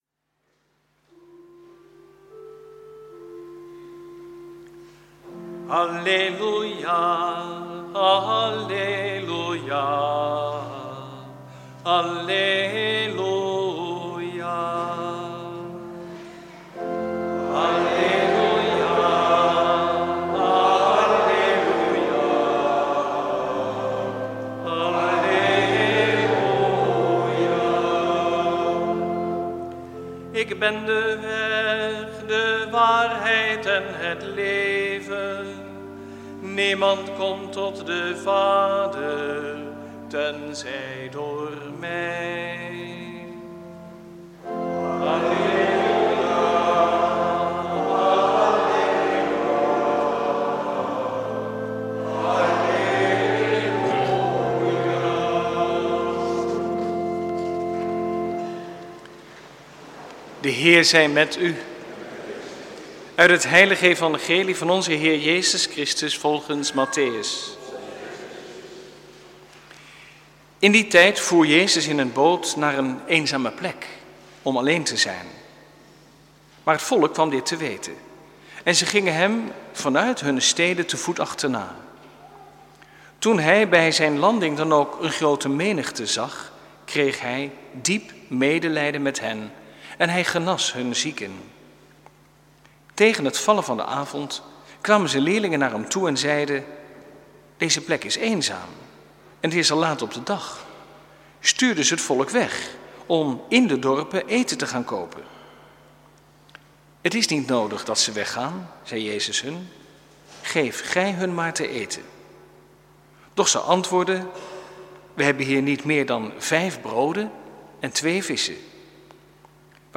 Preek 18e zondag, door het jaar A, 2/3 augustus 2014 | Hagenpreken
Eucharistieviering beluisteren (MP3)